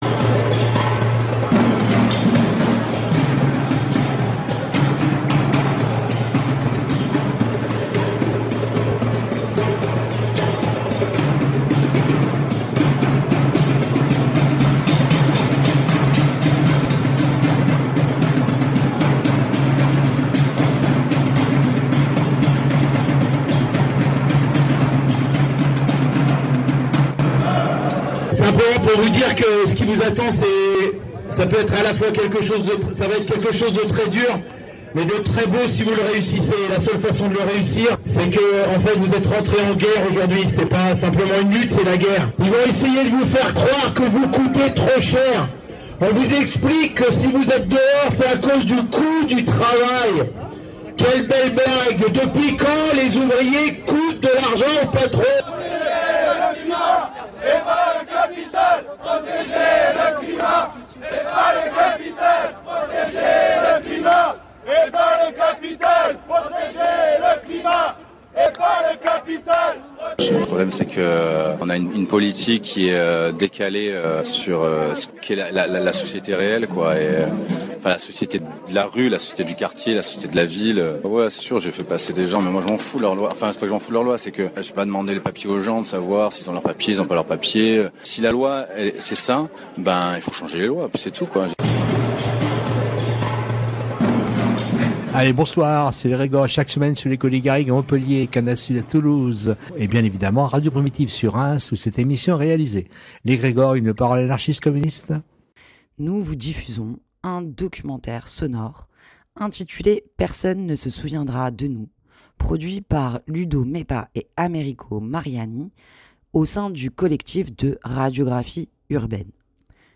documentaire sonore